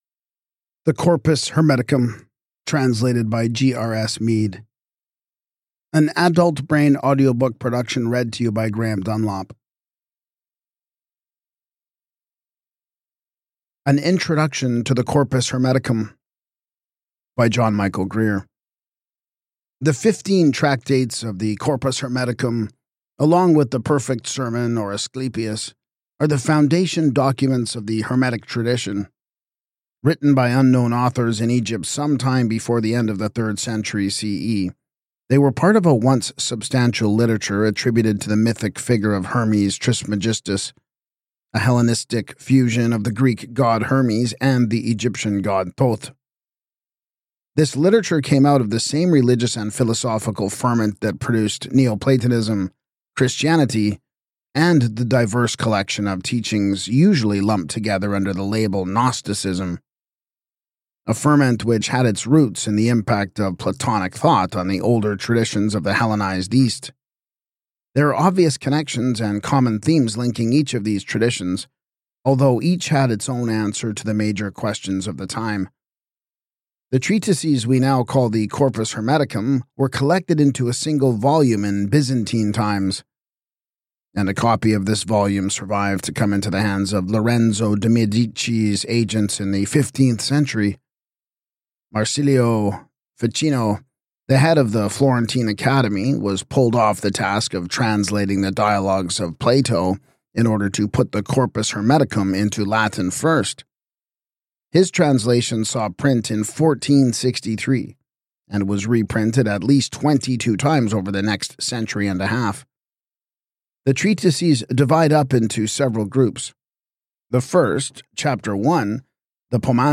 Podcast (audiobooks)